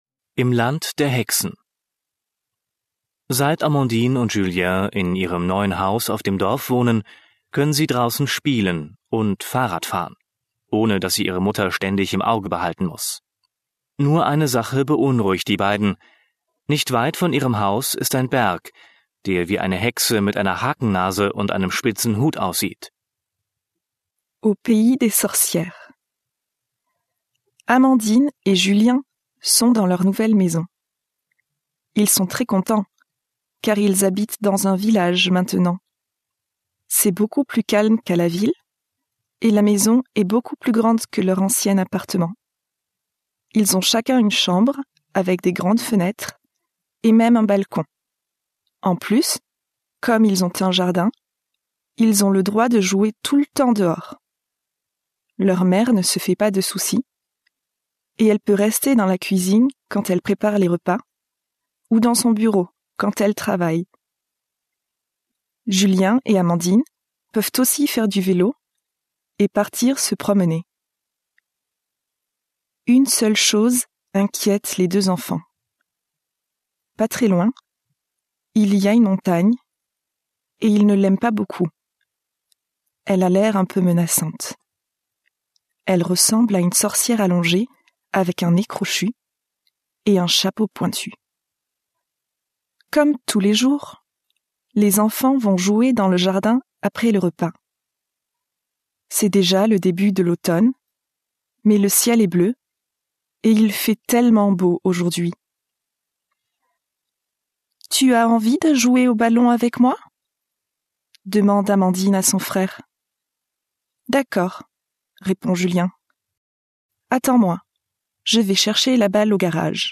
Vor jeder französischen Geschichte ist eine kurze Einführung auf Deutsch gegeben, die den Inhalt der Geschichte kurz zusammenfasst. Alle Geschichten sind von Muttersprachlern gesprochen.